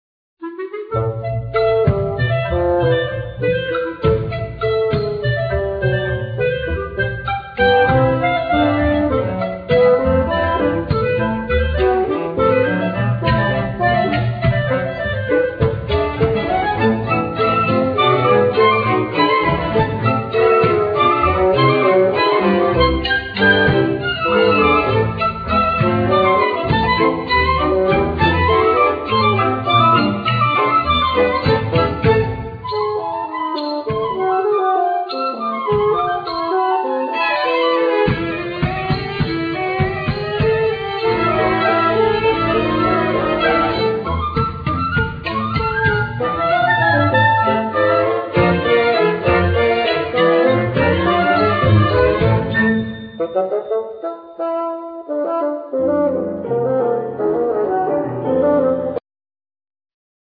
Flute
Clarinet
Basoon
Piano,Percussions
Violin,Trombone
Viola
Cello
Double bass